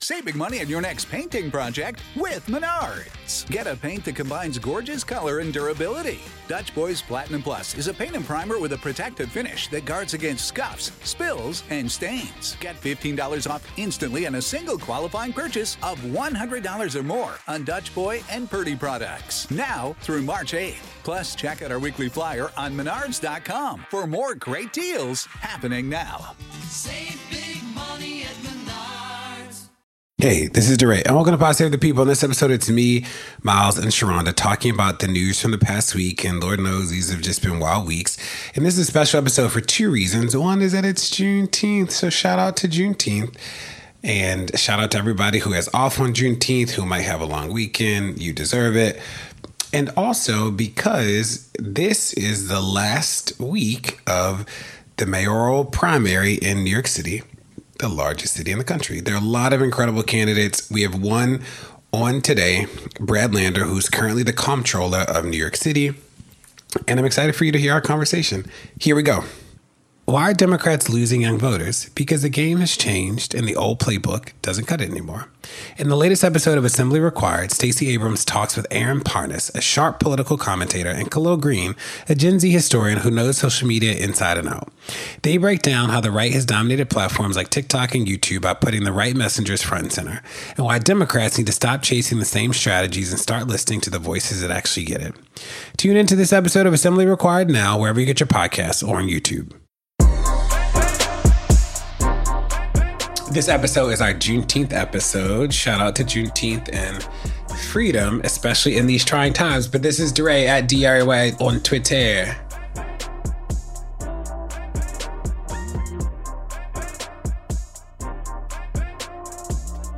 Democratic lawmakers attacked in Minnesota, tensions rise between Iran and Israel, SNAP work requirements threaten food access, tech bros’ bizarre transition to the military, and a reflection on the legacy of Living Single. DeRay interviews NYC Comptroller Brad Lander about his mayoral candidacy.